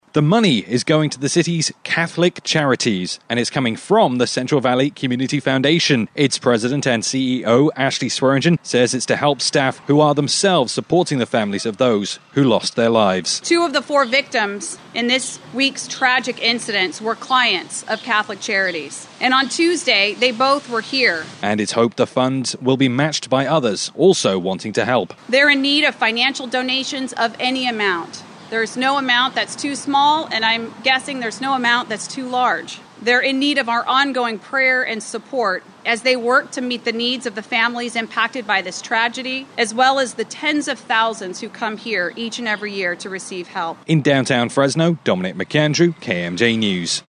The announcement was made outside their facility on Fulton Street Thursday morning, just yards from the parking lot where one of the three victims was shot and later died.